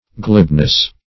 Glibness \Glib"ness\, n.